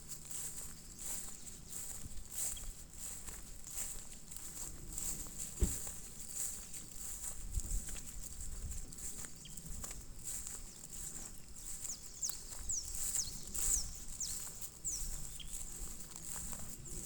Small-billed Elaenia (Elaenia parvirostris)
Location or protected area: Dique El Cadillal
Condition: Wild
Certainty: Recorded vocal
Fiofio-pico-corto.mp3